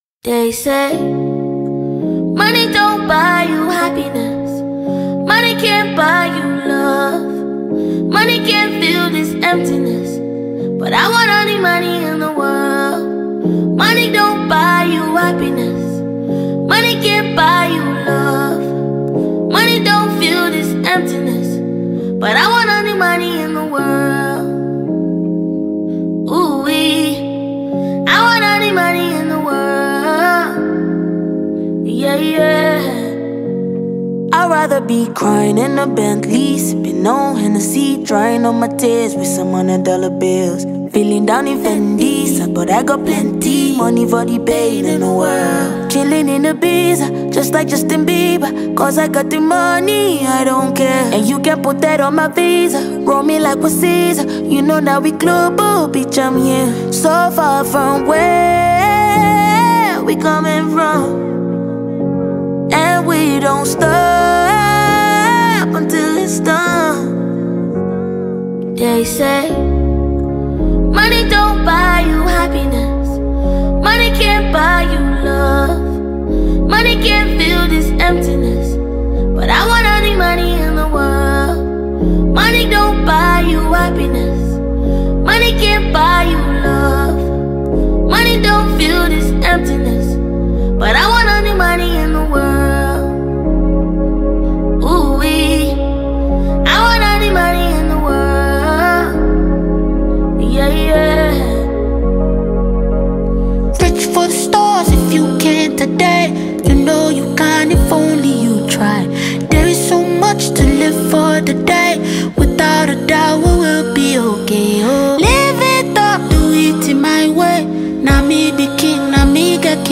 Nigerian singer and songwriter